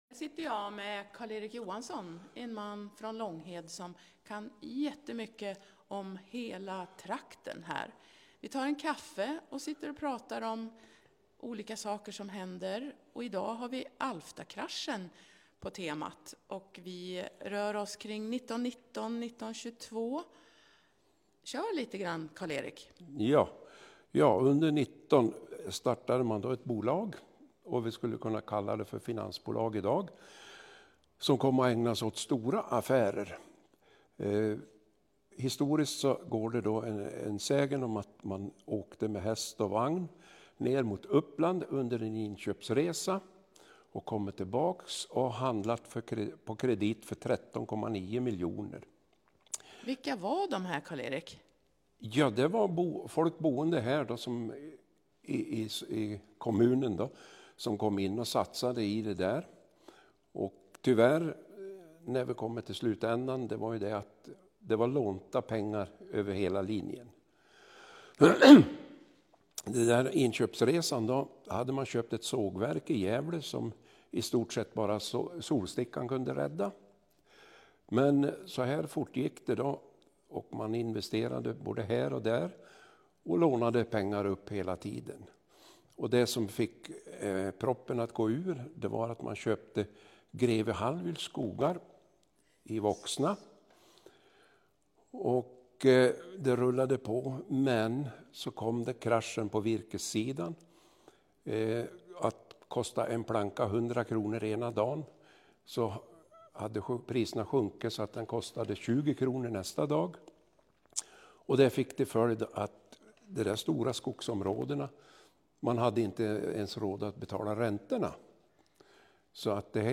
i samtal